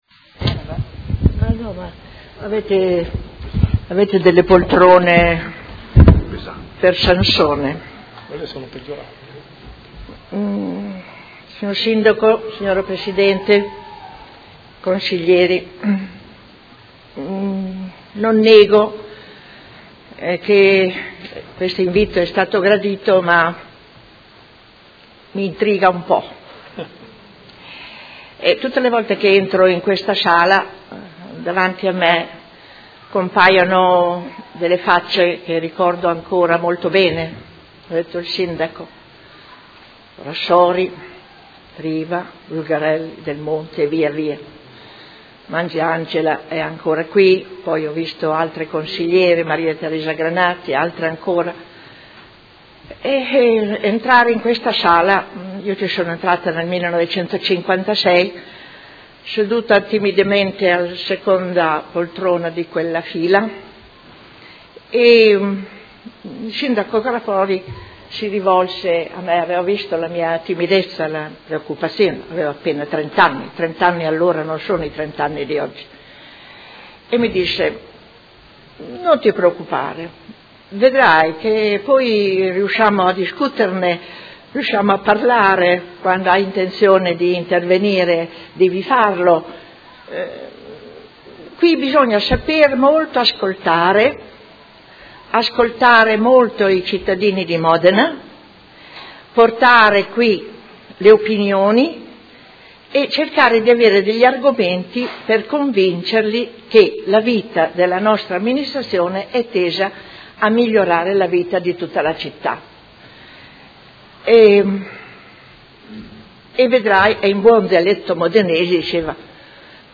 Seduta del 20/04/2016. Celebrazione del 70° dall'insediamento del primo Consiglio Comunale di Modena dopo il periodo fascista